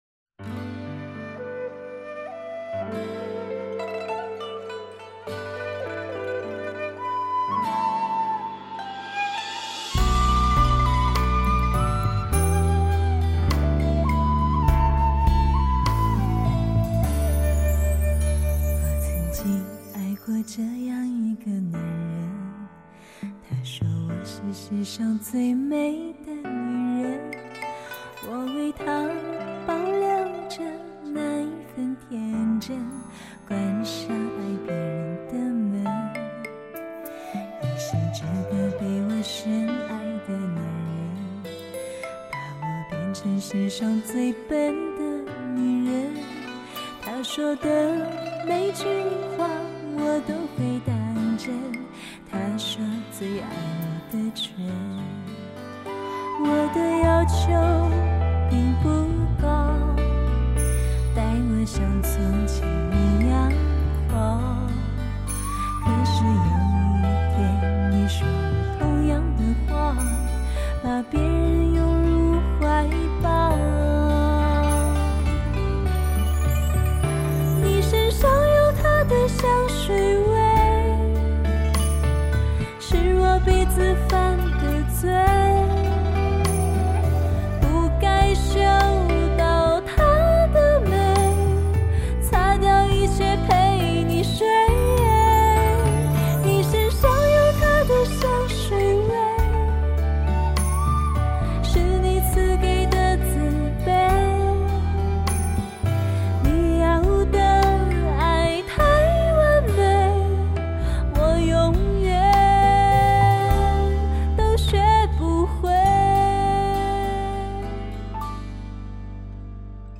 世界顶级录音设备全面满足听觉享受
天籁女声无法抗拒
音乐实在出采，贝司，鼓和电钢琴配合美妙绝伦，
琵琶画龙点睛，说出爱情是不可以拿来考验的。